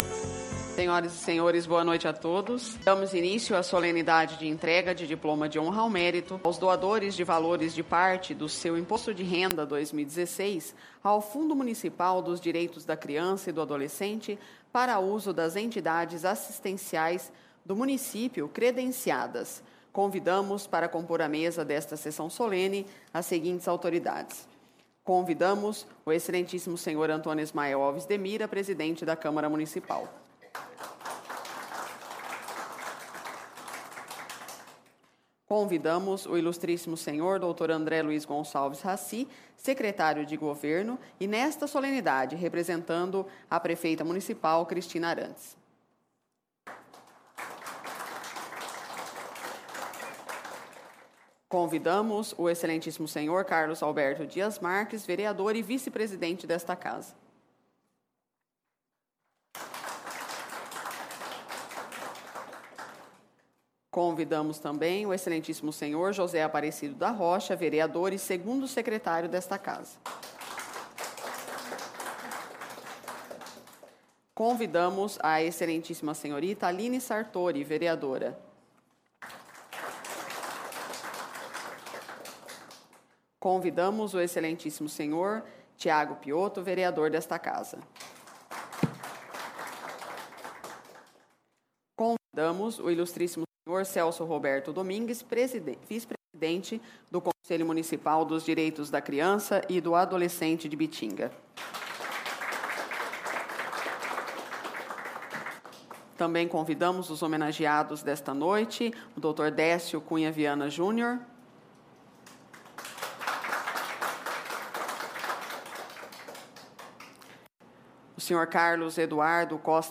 Sessões Solenes/Especiais